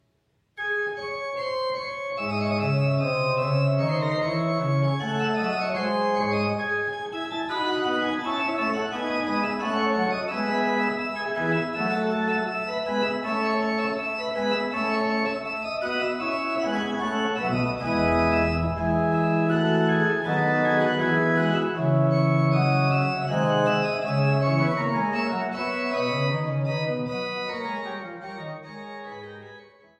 an der historischen Orgel zu Niederndodeleben
Orgel